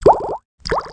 1 channel
DRIPS.mp3